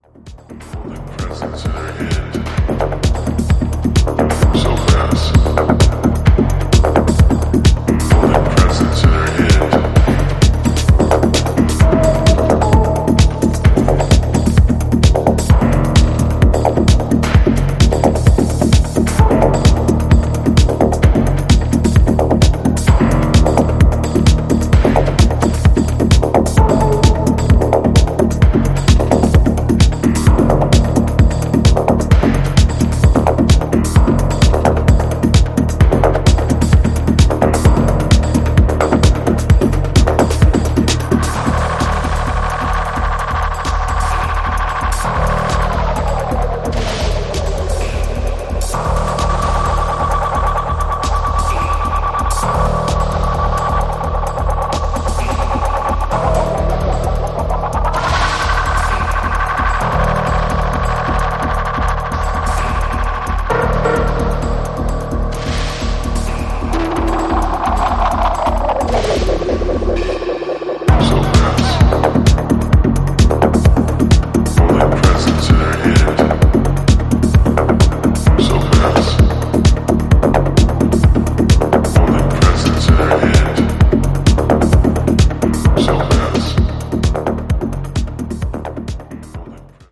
本作では、EBMやアシッド等の要素を盛り込んだダークでソリッドなエレクトロ・ハウスを展開しています。